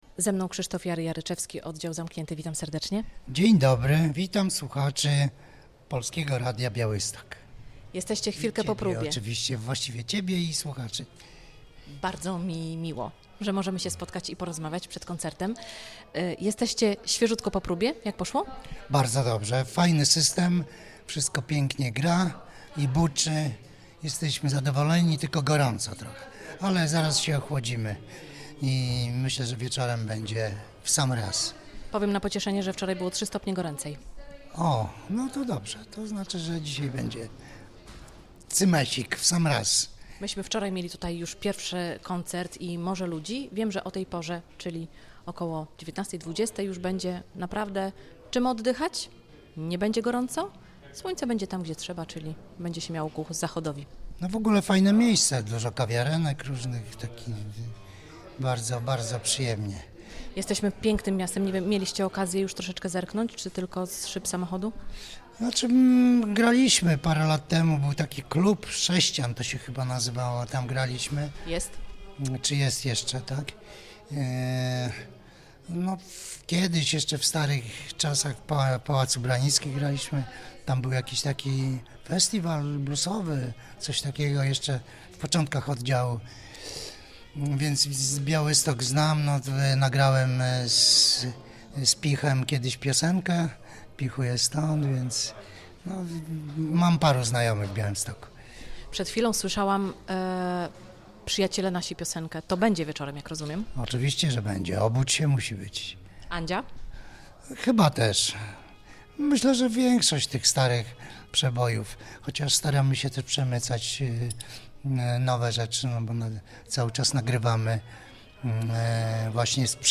Jest też nasze plenerowe studio - nadajemy z Rynku Kościuszki od 12:00 do 17:00.